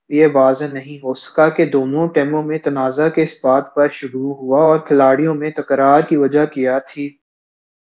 deepfake_detection_dataset_urdu / Spoofed_TTS /Speaker_13 /14.wav